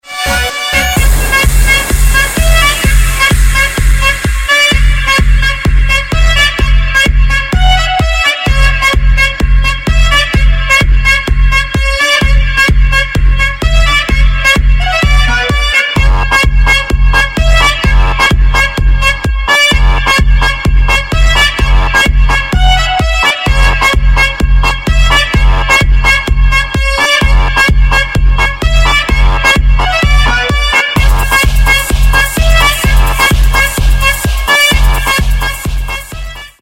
• Качество: 192, Stereo
труба
качает